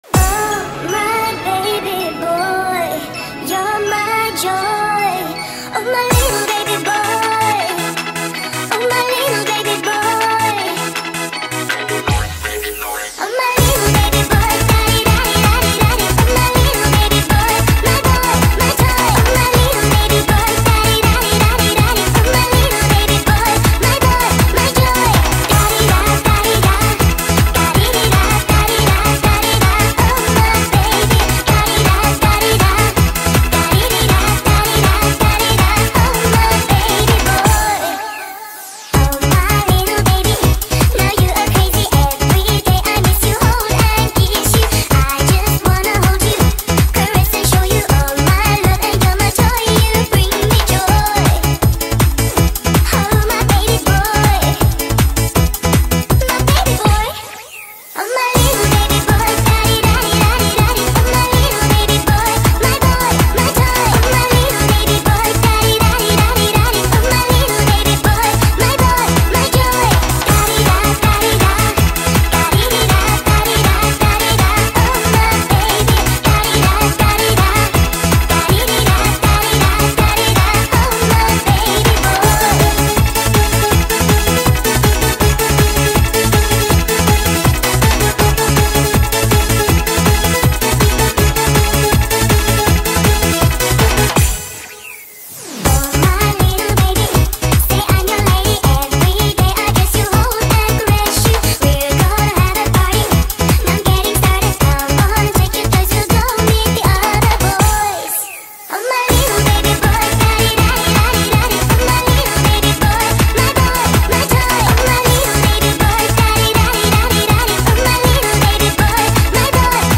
شاد
عاشقانه